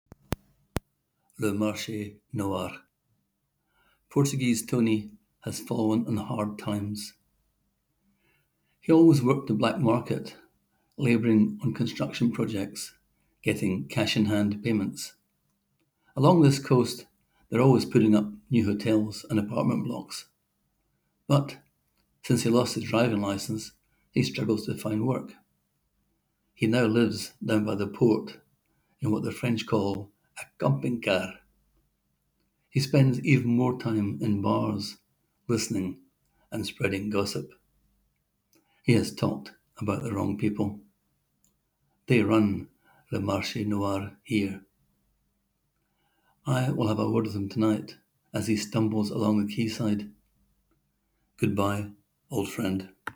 Click here to hear the author read his words: